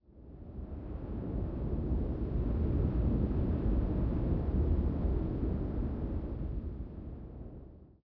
cave10.ogg